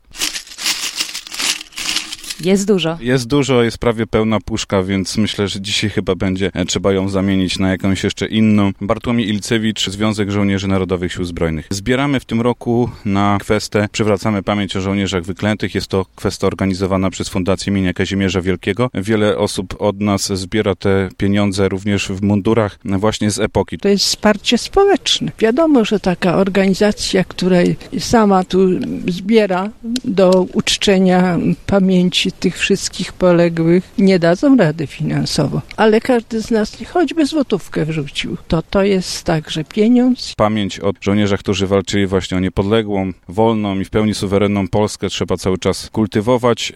Nius o kwestach.mp3